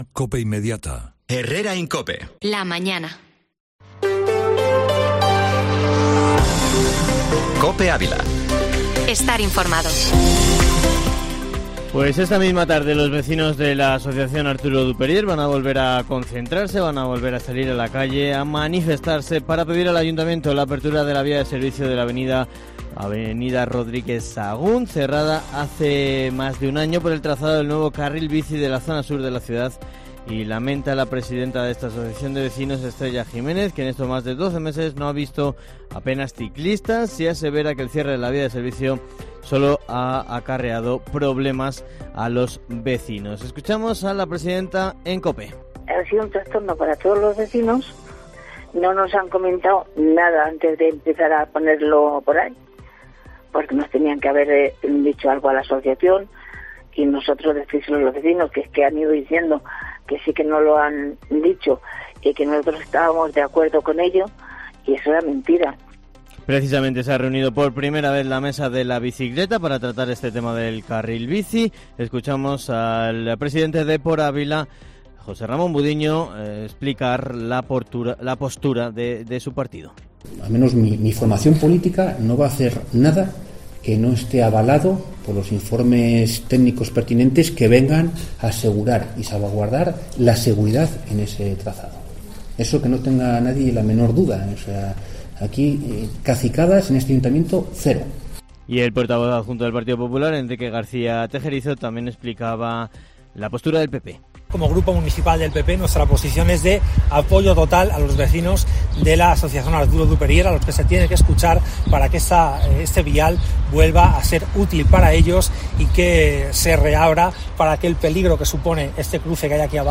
Informativo Matinal Herrera en COPE Ávila